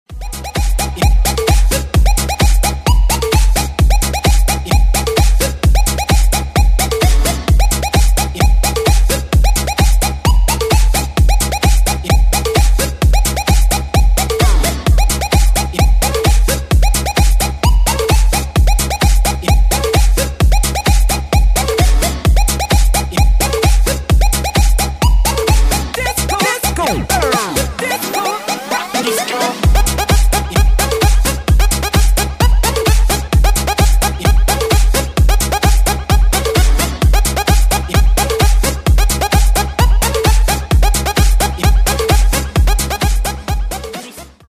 громкие
женский голос
dance
Electronic
EDM
электронная музыка
club
энергичные
electro house